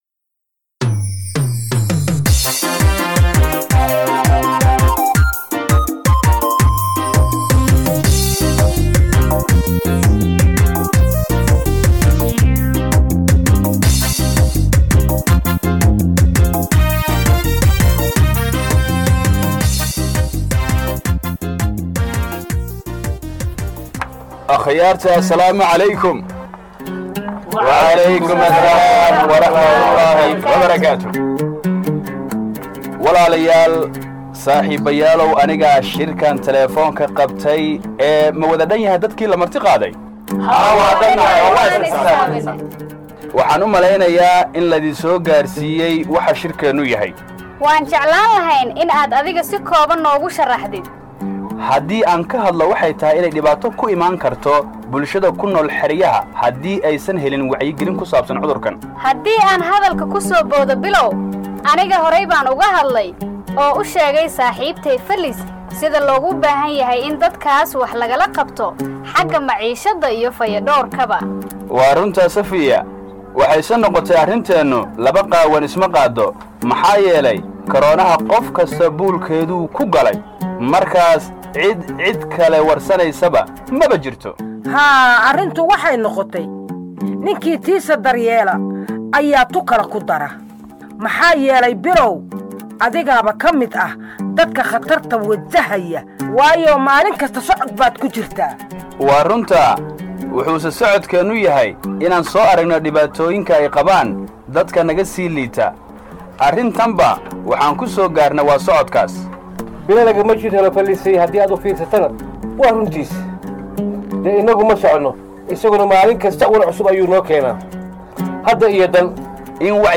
Scene 19: ‘Residents discuss health risks in IDP camps and how to help’